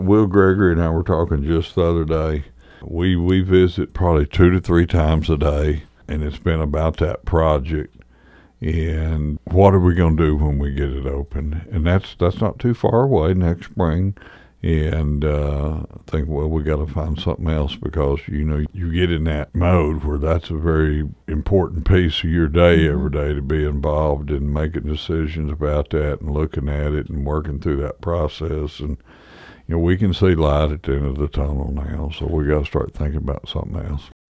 Although there is still plenty of work left to do, Mayor Adams says he is already looking to what’s next for the city.